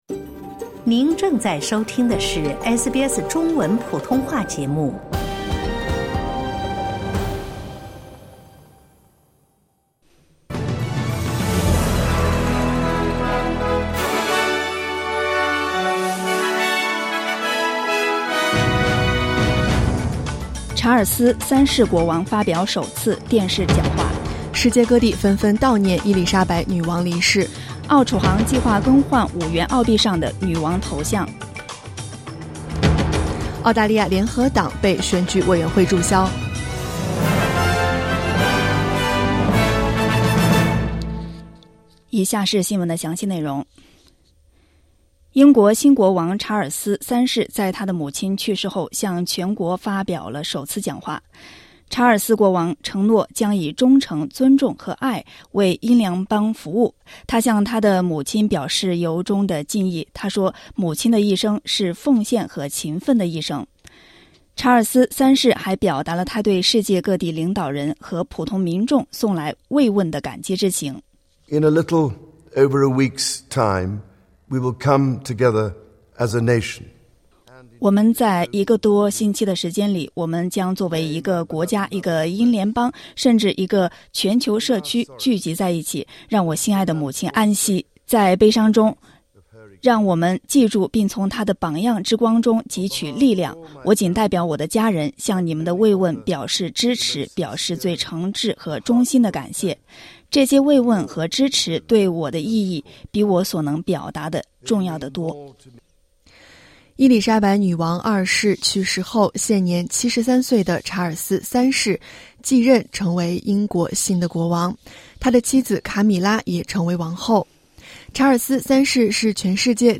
SBS早新闻（2022年9月10日）